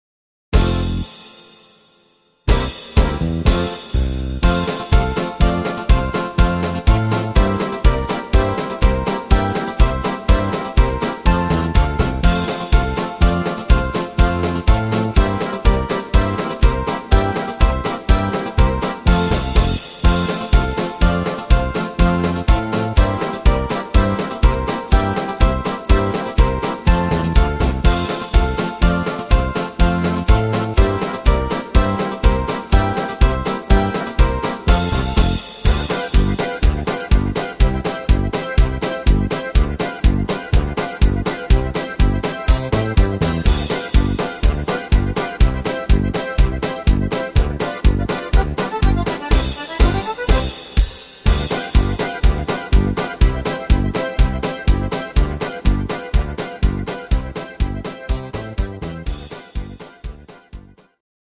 instrumental Akkordeon